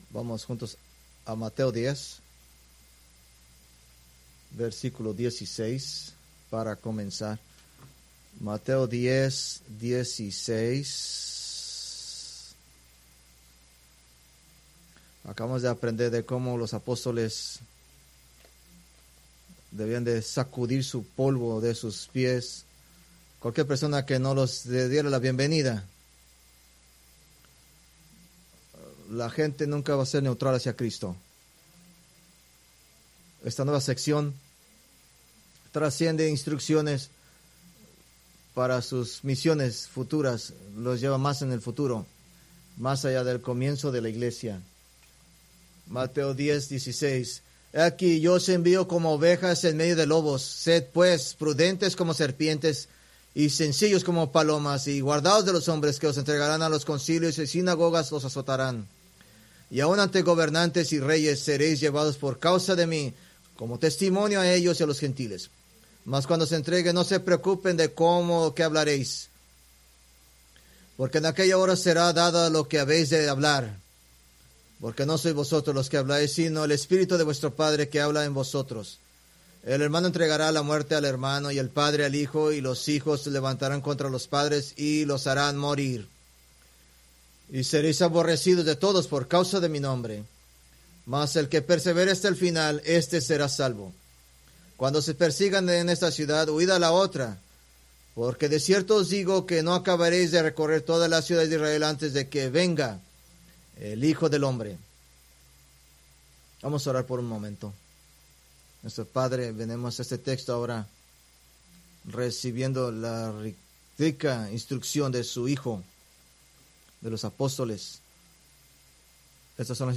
Preached May 4, 2025 from Mateo 10:16-23